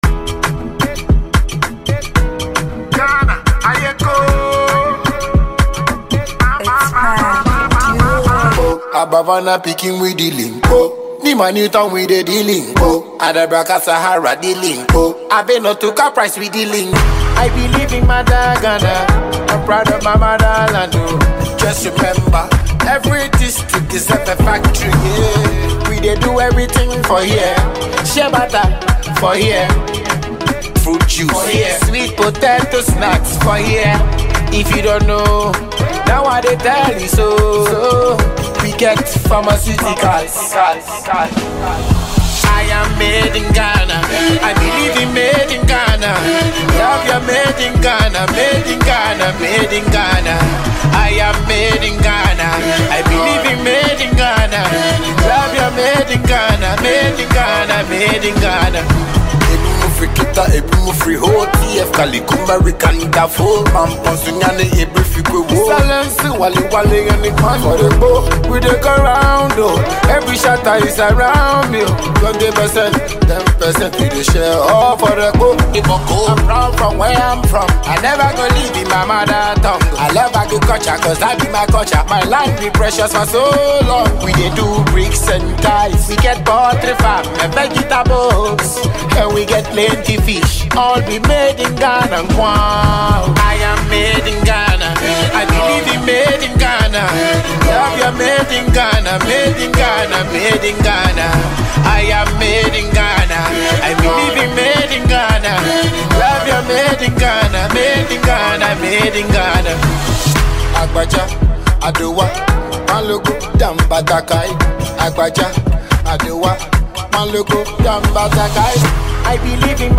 Ghanaian reggae and dancehall